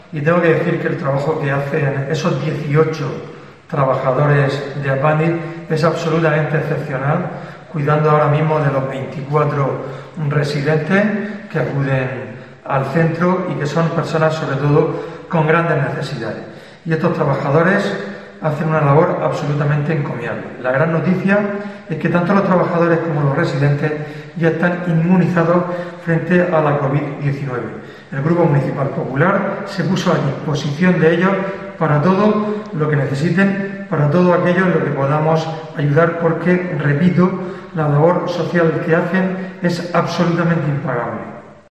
Fulgencio Gil, portavoz del PP en Lorca sobre APANDIS